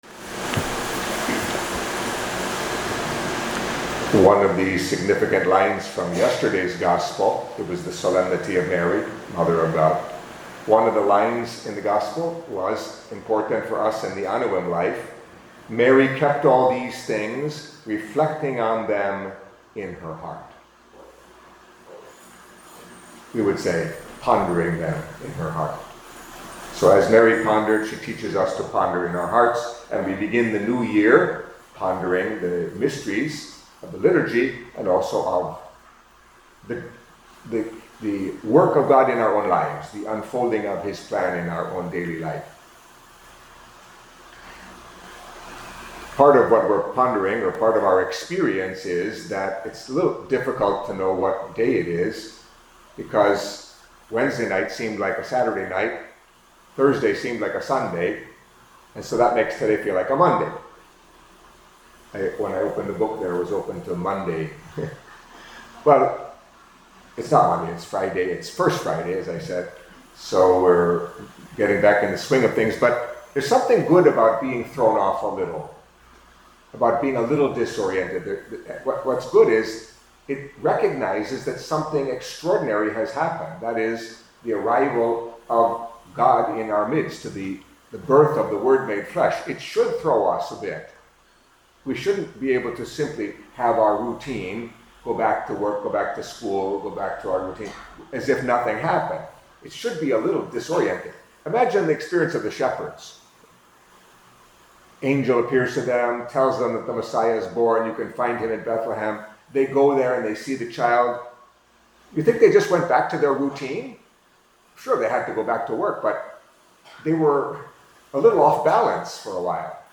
Catholic Mass homily for Friday, January 2nd